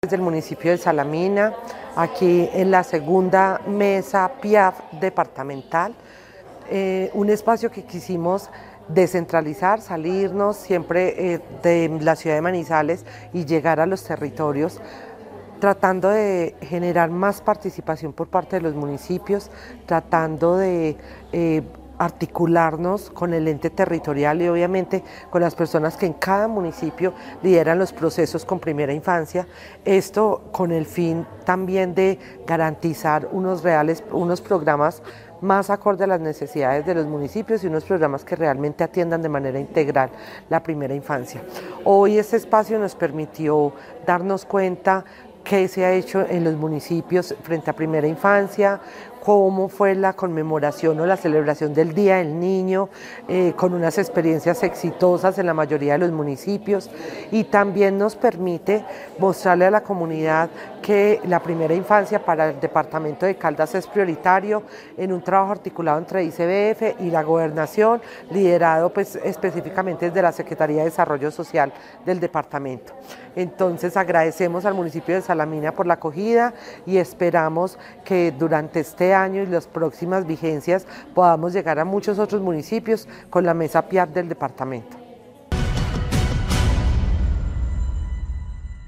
Sandra Milena Gaviria Ramírez, Directora Regional (e) del ICBF